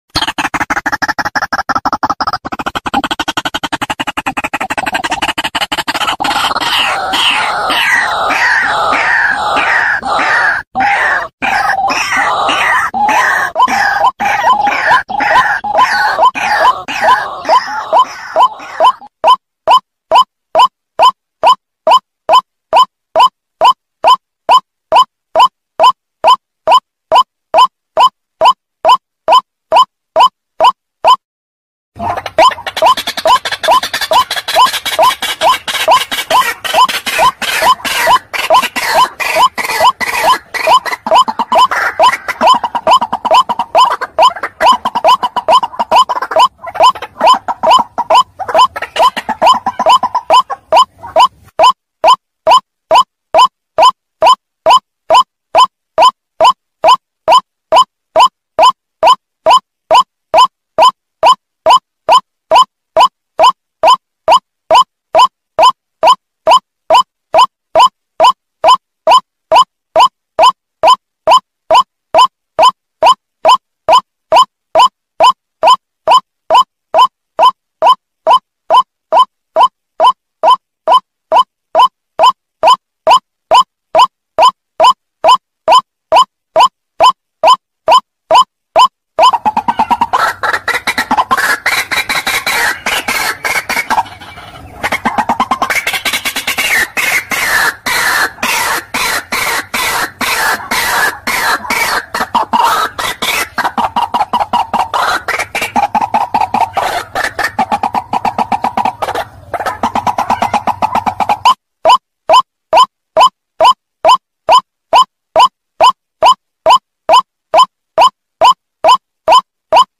Dengarkan suara burung Ruak Ruak asli yang jernih dan bersih mp3.
Suara Burung Ruak Ruak Bersih Jernih
Tag: suara Kareo Padi/ Ruak-ruak suara ladang/ rawa
suara-burung-ruak-ruak-bersih-id-www_tiengdong_com.mp3